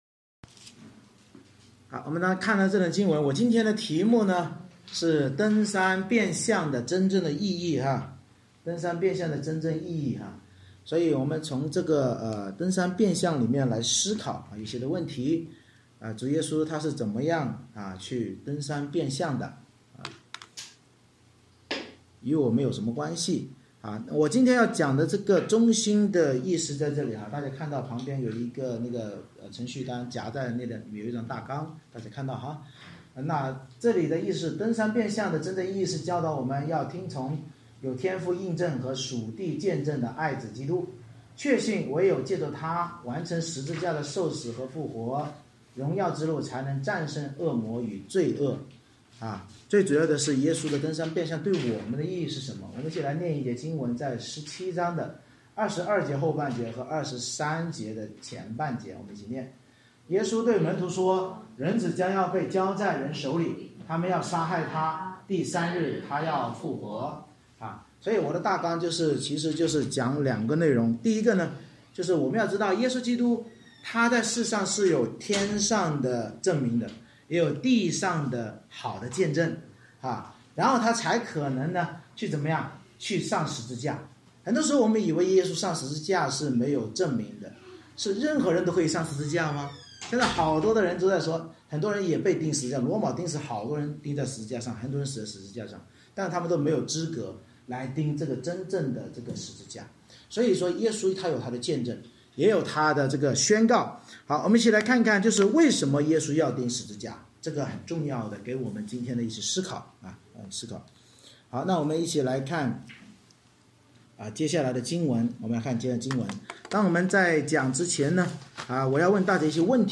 马太福音17章 Service Type: 主日崇拜 登山变相真正意义是教导我们要听从有天父印证和属地见证的爱子基督，确信唯有藉祂完成十架受死并复活的荣耀之路才能战胜恶魔与罪恶。